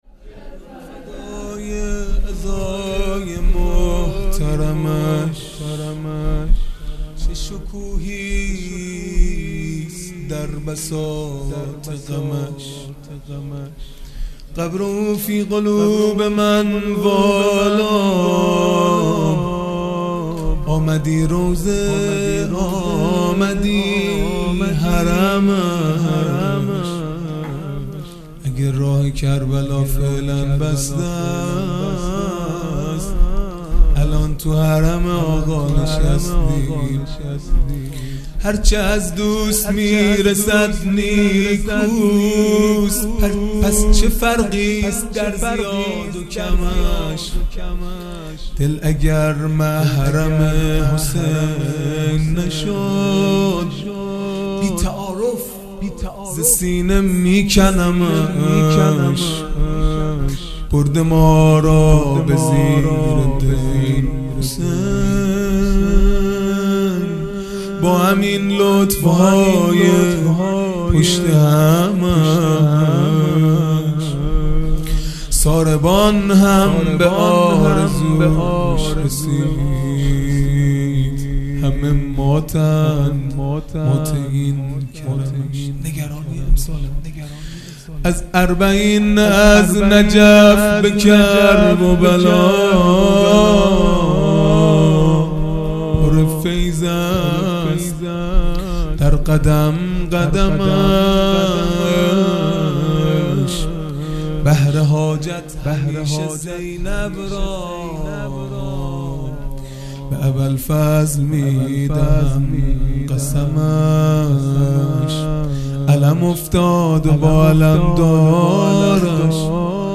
خیمه گاه - هیئت بچه های فاطمه (س) - مناجات پایانی | به فدای عزای محترمش
دهه اول محرم الحرام ۱۴۴٢ | شب تاسوعا